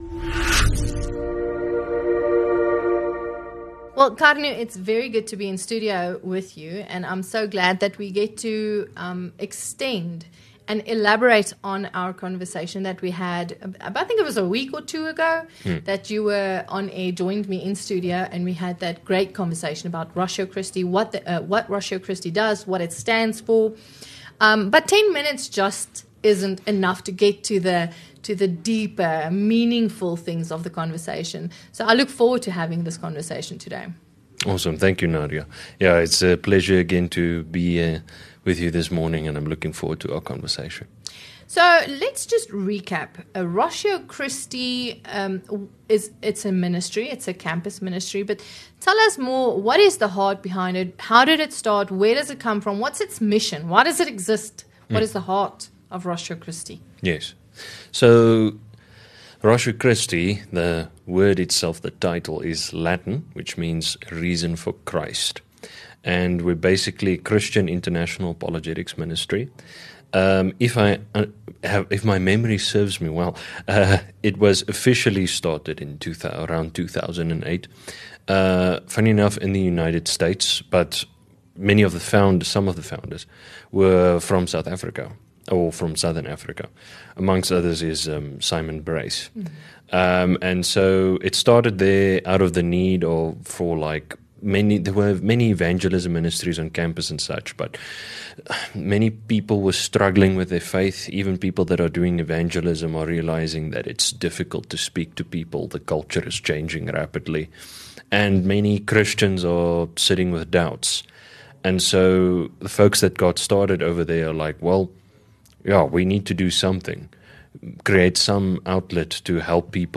From the value of Christian apologetics to exploring the Christian worldview, they delve into the believer’s essential responsibility to provide biblically sound answers for their faith. They also highlight the importance of engaging secular ideas with Christian truth. Grab your coffee, buckle up, and enjoy the conversation!